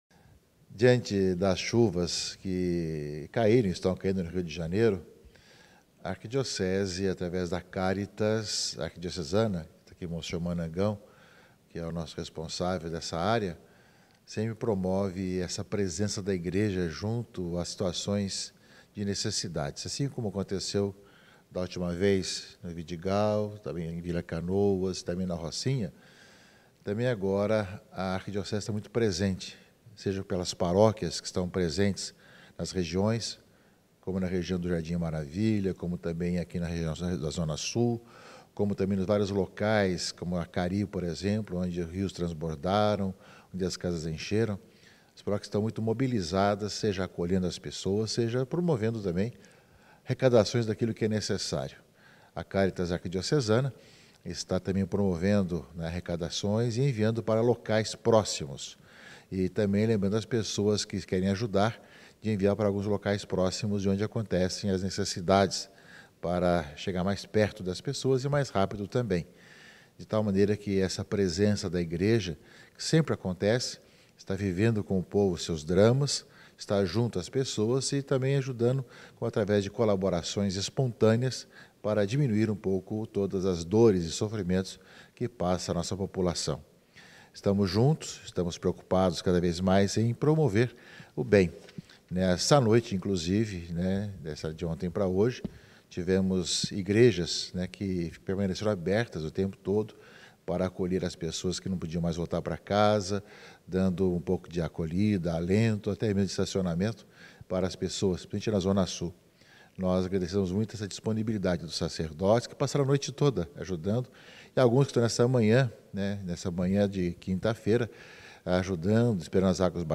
Arcebispo do Rio fala sobre as tragédias que marcaram a cidade nos últimos dias
Dom-Orani-fala-sobre-as-chuvas-no-Rio.mp3